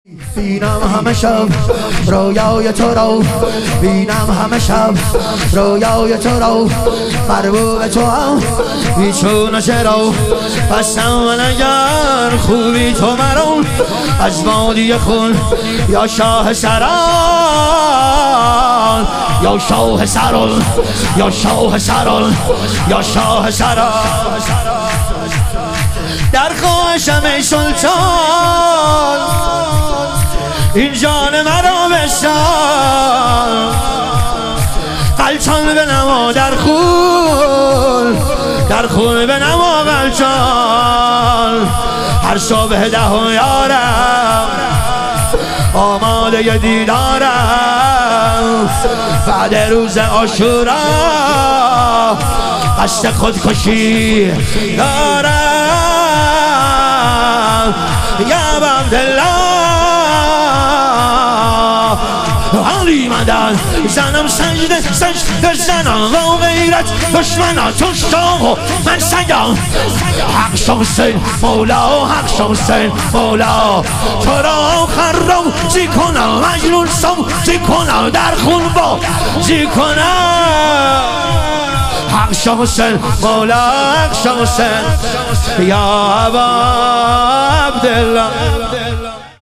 ظهور وجود مقدس امام جواد و حضرت علی اصغر علیهم السلام - شور